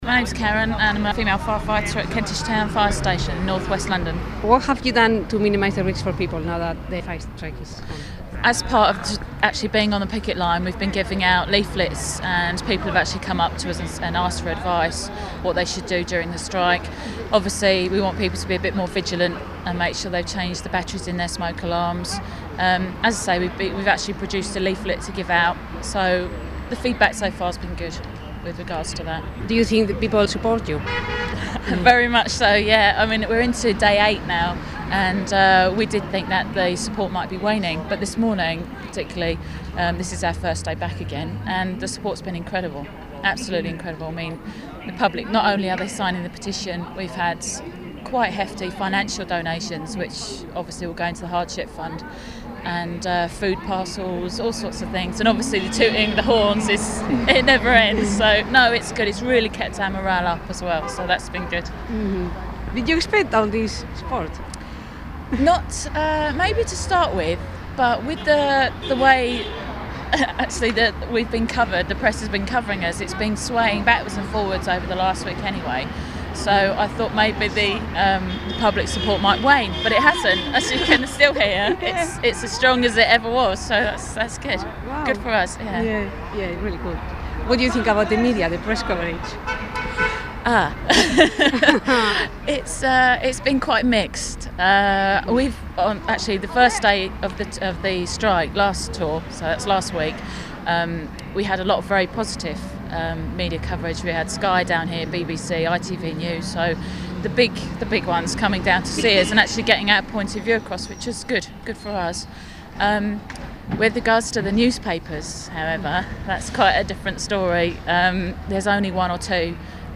full interview1
Here is one of the interviews, with very light editing (about 7 minutes).